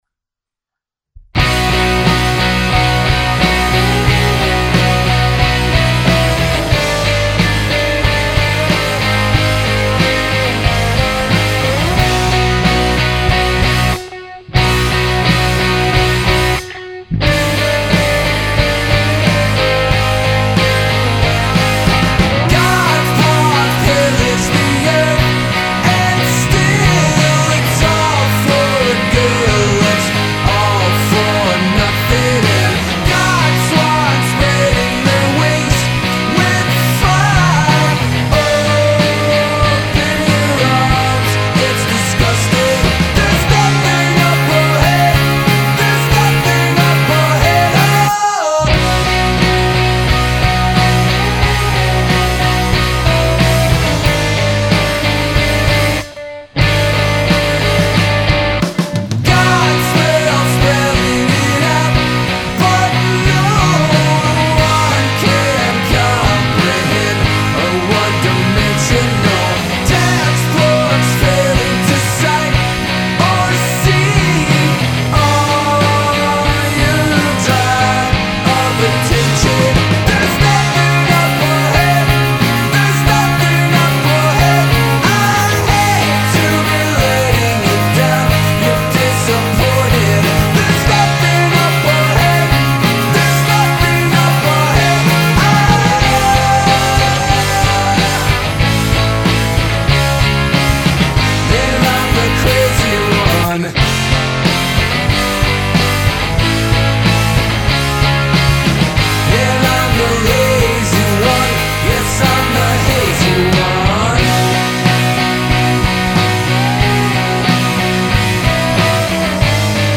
VOX, Guitars, Synths, Percussion